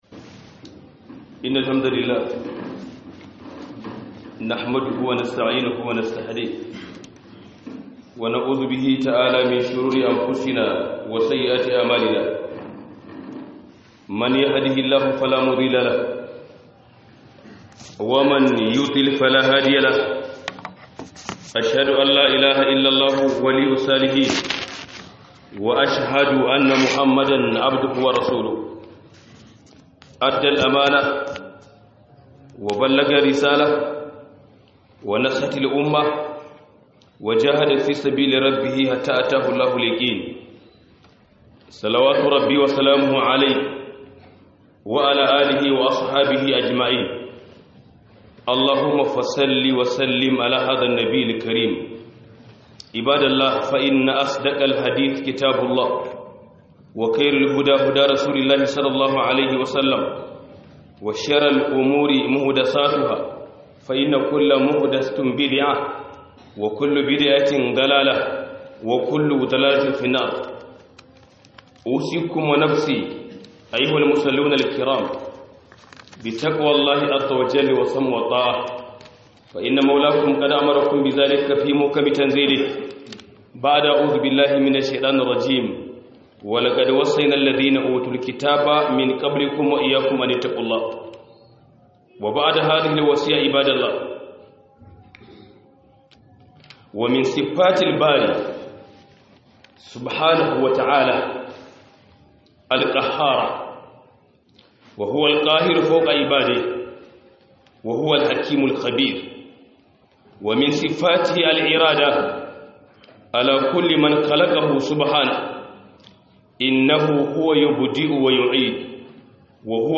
071 Huduba Mai Taken Wassu Daga Cikin Suffofin Allah